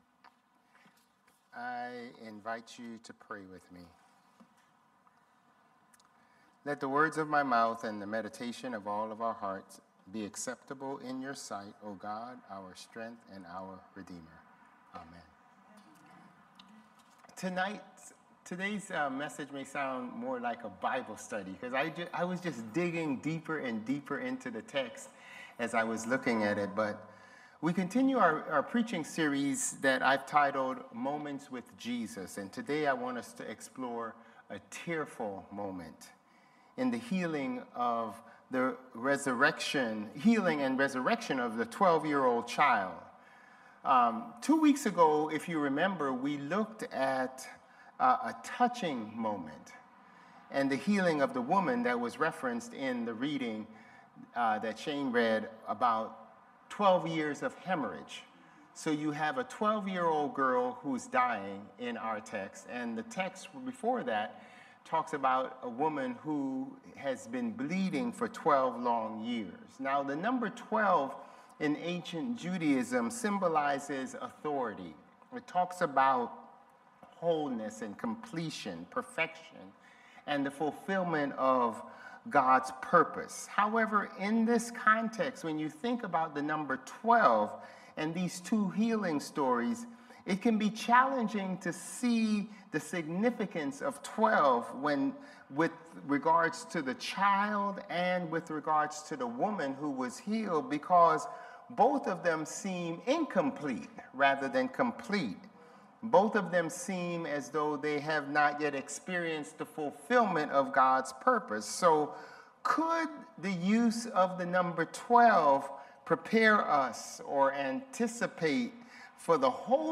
Sermons | Bethel Lutheran Church
July 6 Worship